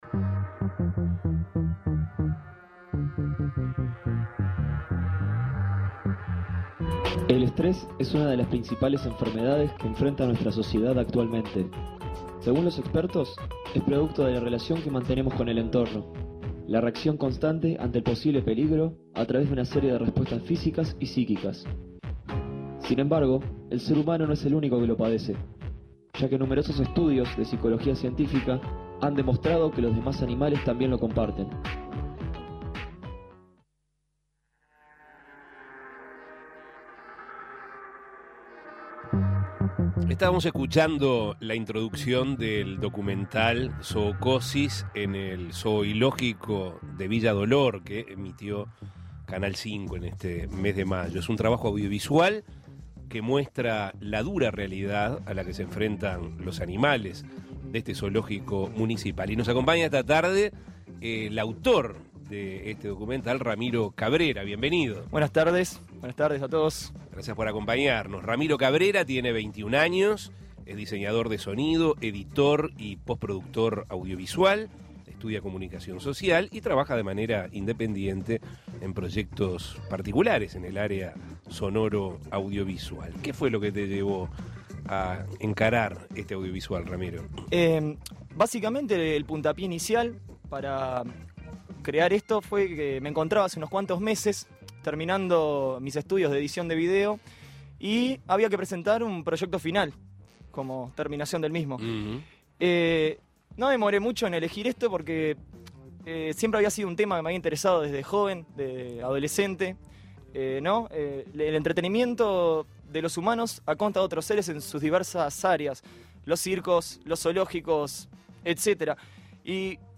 Se trata de un trabajo audiovisual que muestra la dura realidad a la que se enfrentan los animales del Zoológico de la Intendencia Municipal de Montevideo. Escuche la entrevista.